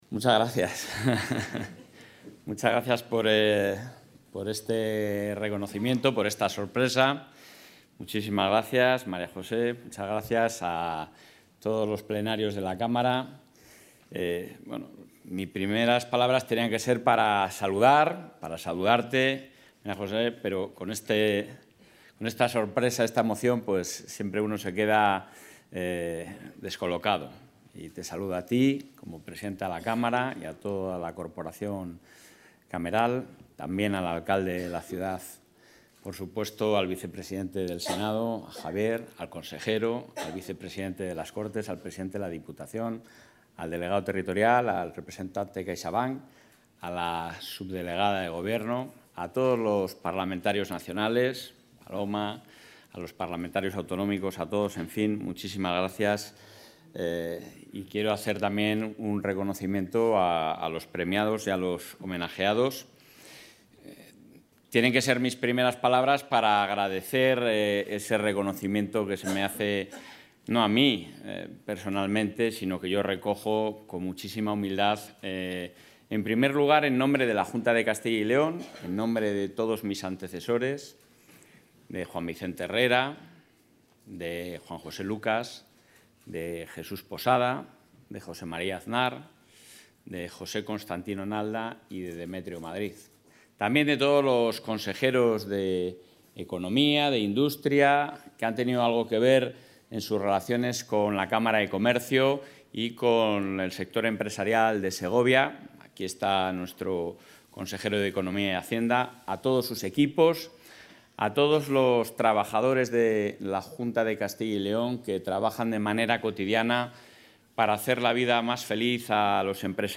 Intervención del presidente de la Junta.
El presidente de la Junta de Castilla y León, Alfonso Fernández Mañueco, ha asistido hoy a la entrega de los Premios Real Sello de Paños, en el acto de celebración del 120 Aniversario de la Cámara de Comercio de Segovia, donde ha recibido, en nombre del Ejecutivo autonómico, la Medalla como Plenario de Honor de esta institución.